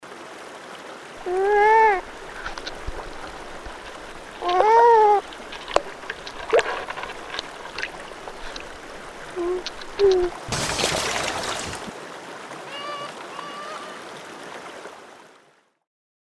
Звук бобров
Звук бобра в воде:
zvuk-bobra-v-vode.mp3